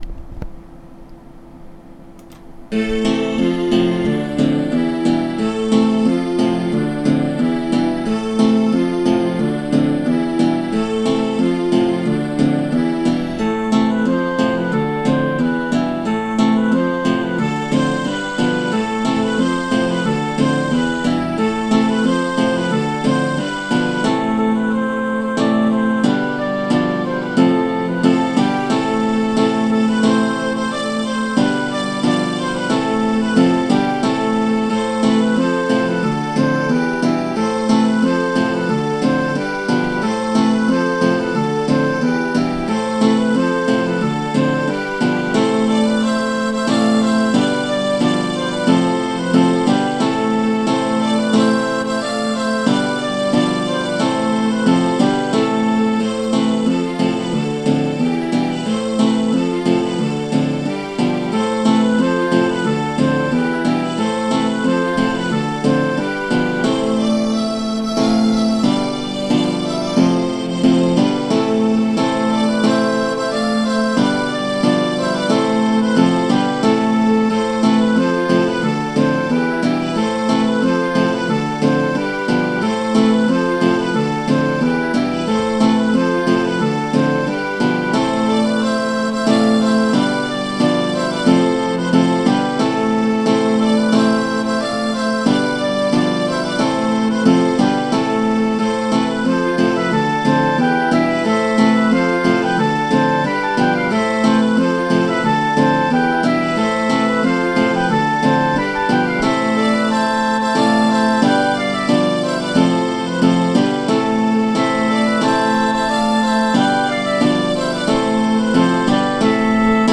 Les deux contrechants sont ici très simples. Sur le mp3, il y a une variation que je n'ai pas transcrite.
De même, je n'ai pas refait l'enregistrement, un peu ancien, dont le son n'est pas au mieux.